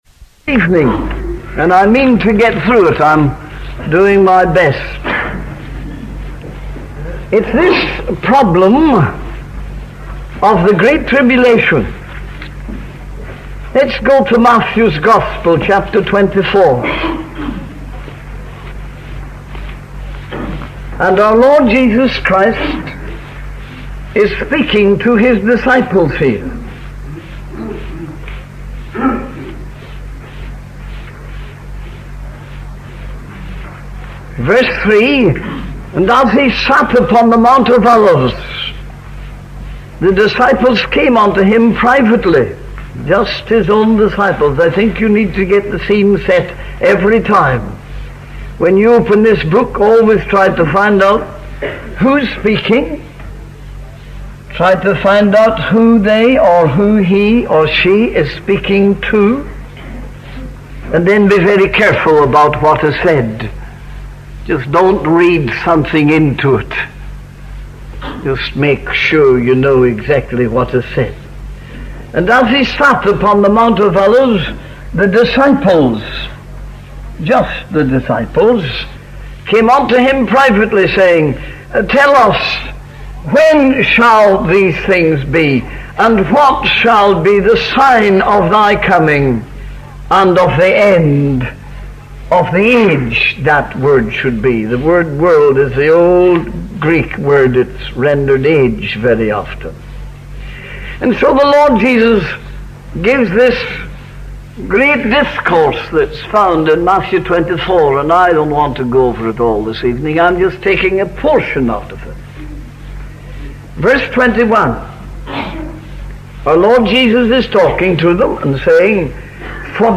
In this sermon, the preacher discusses the opening of the fourth seal in the book of Revelation. The fourth seal reveals a pale horse, symbolizing death, and hell follows with it.